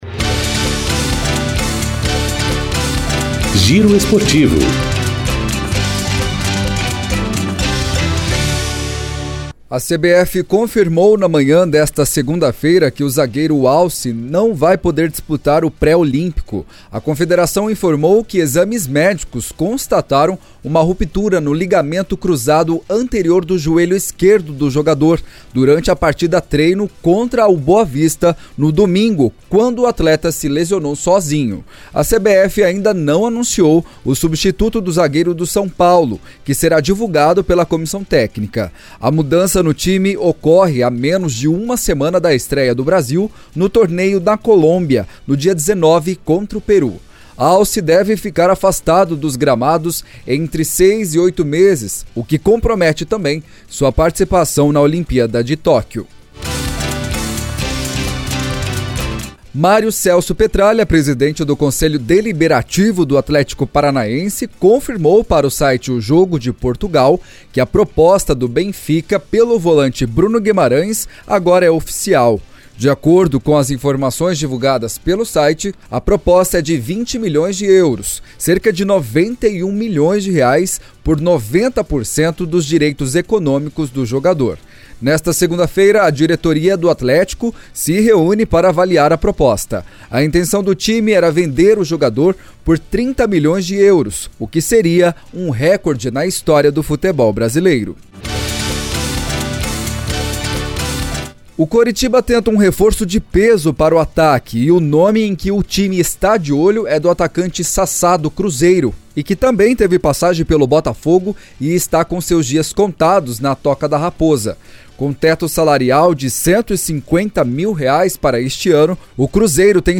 Giro Esportivo COM TRILHA